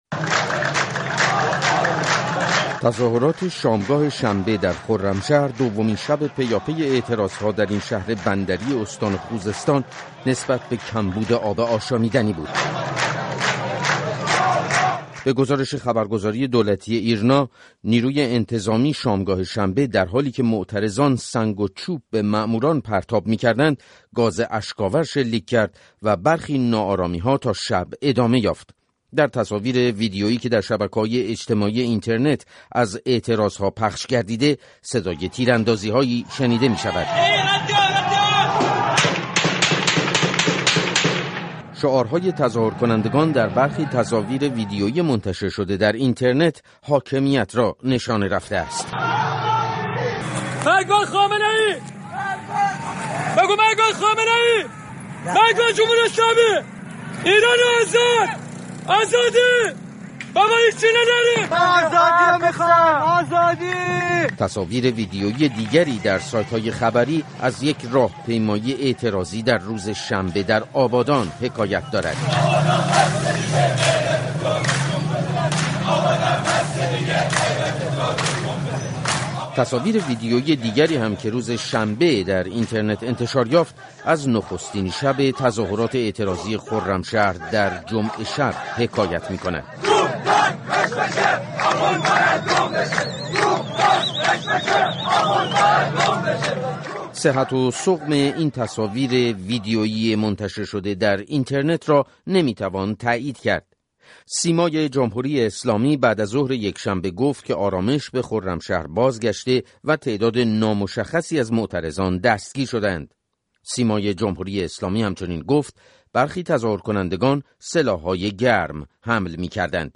گزارش رادیویی از درگیری‌های گسترده شنبه شب در خرمشهر